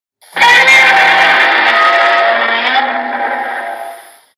GodzillaRoar.mp3